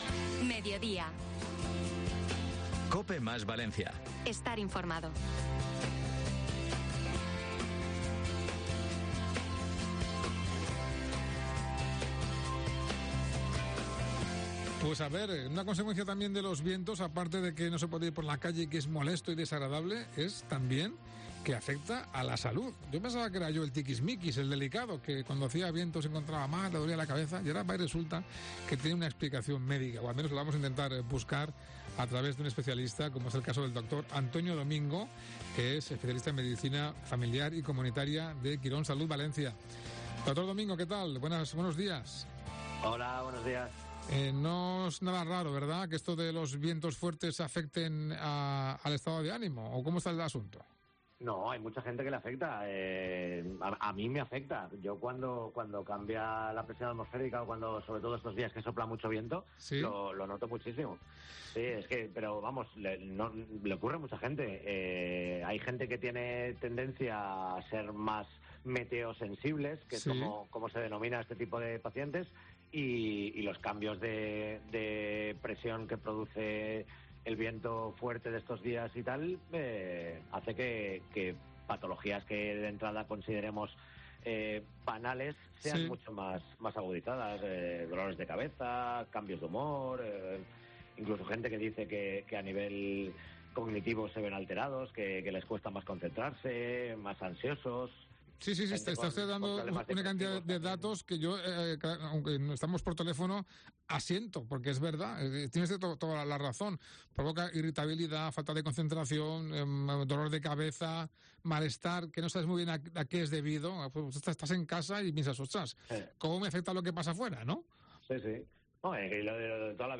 Muchas son las veces que hemos escuchado la frase de "me duele la cabeza y es por el viento" pero, ¿existe evidencia científica detrás de este hecho? Un experto lo explica en COPE